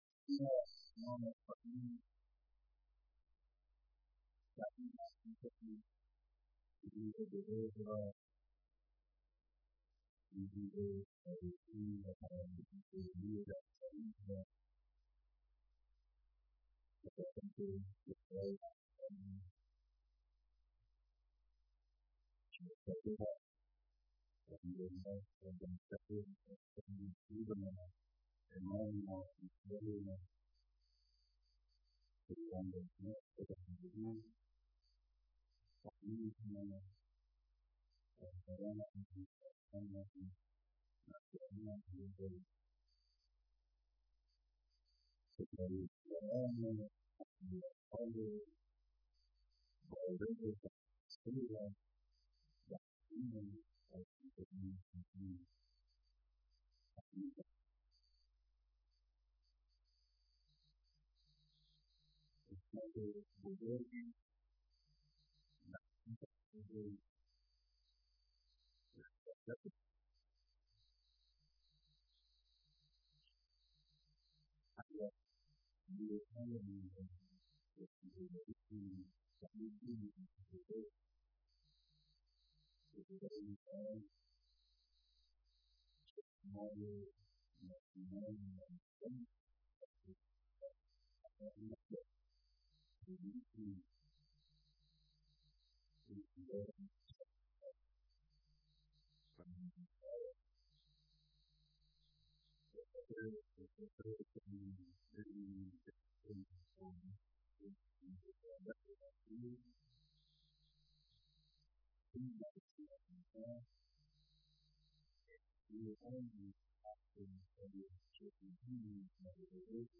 بيانات در ديدار جمعى از مداحان سراسر كشور